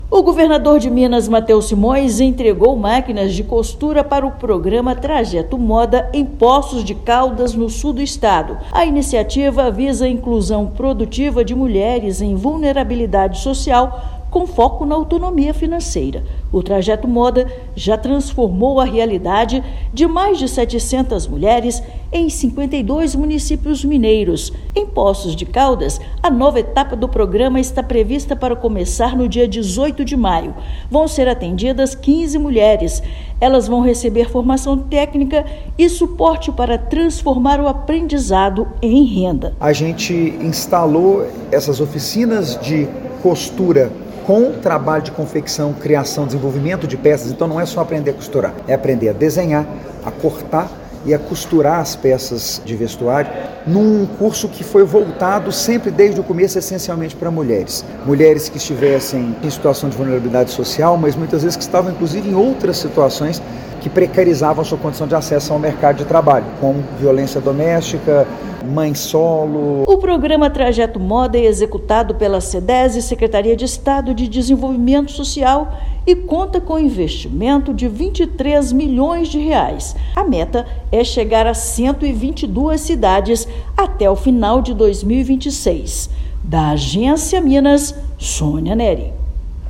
Estimativa é que iniciativa alcance 1,7 mil mulheres, em 122 municípios, possibilitando autonomia financeira e geração de renda. Ouça matéria de rádio.